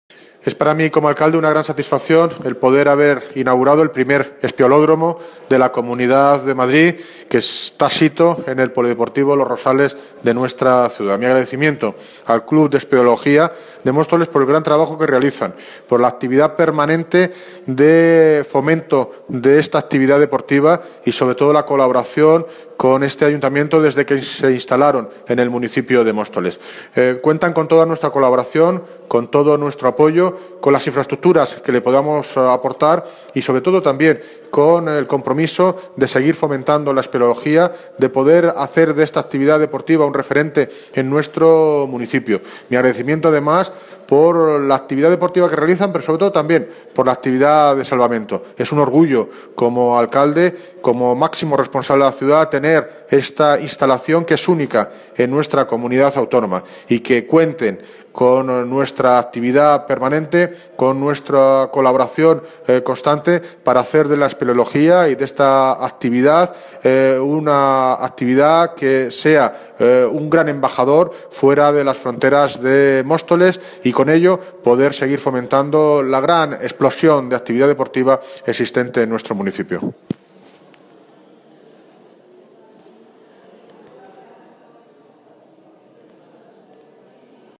Audio - David Lucas (Alcalde de Móstoles) Sobre Espeleodromo